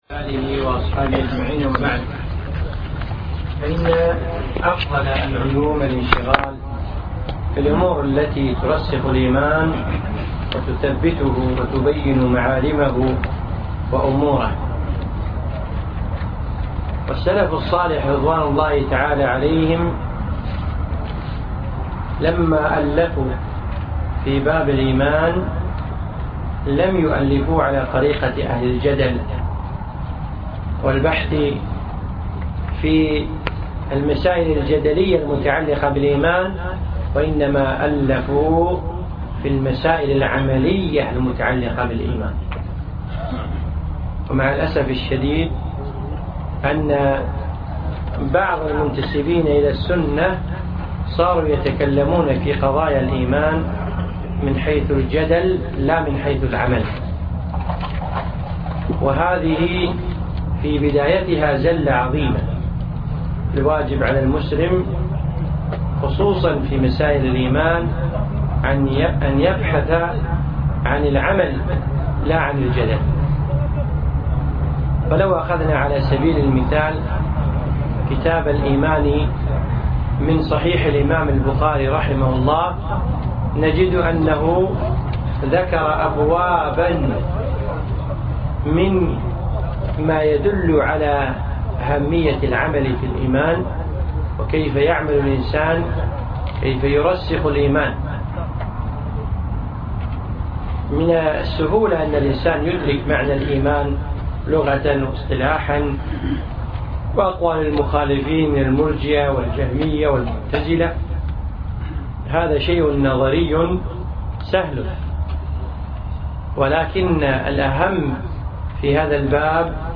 أقيم هذا الدرس بدولة الإمارات